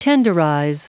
Prononciation du mot tenderize en anglais (fichier audio)
tenderize.wav